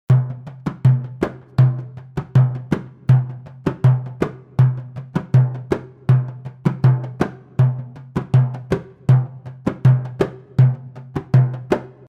bendir.mp3